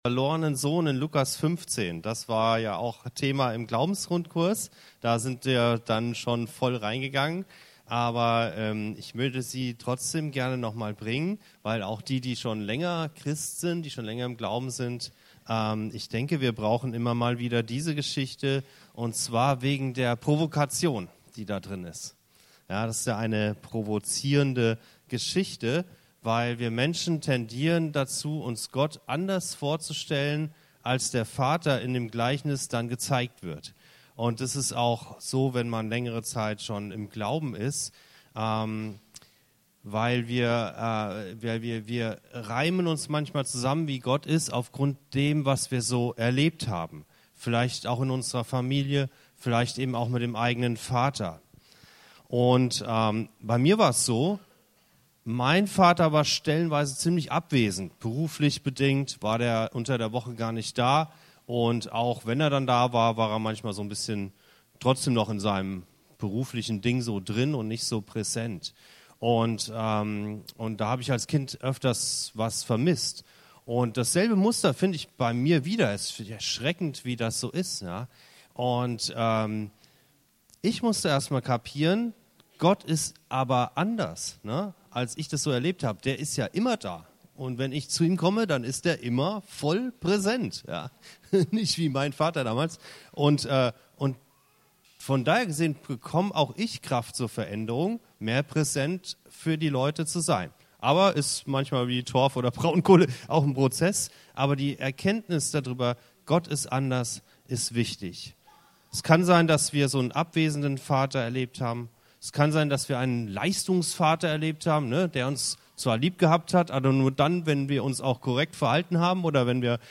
Der Sohn, der alles falsch macht und der Vater, der alle Erwartungen sprengt- Lukas 15, 11-32 ~ Anskar-Kirche Hamburg- Predigten Podcast